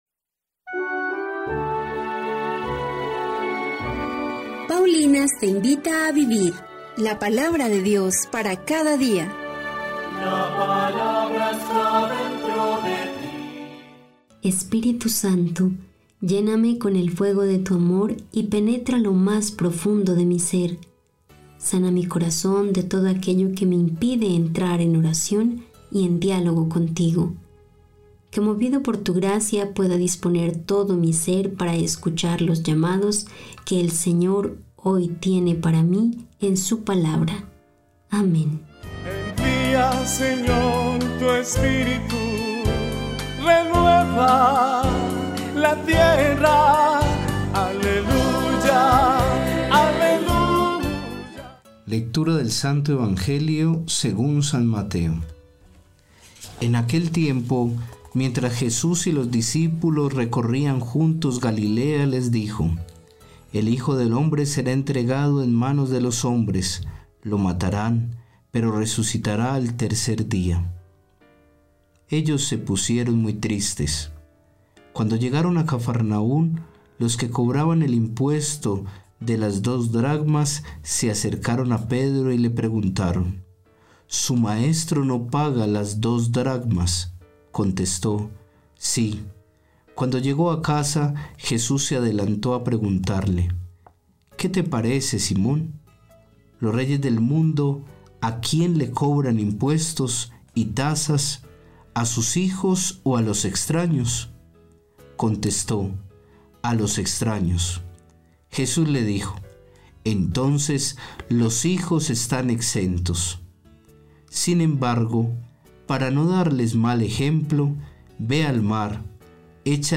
Lectura del libro del Deuteronomio 6, 4-13